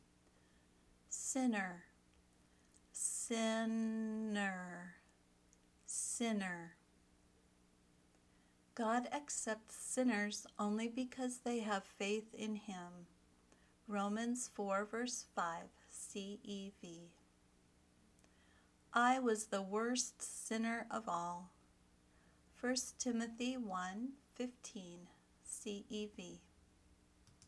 ˈsɪ nər (noun)
vocabulary word – sinner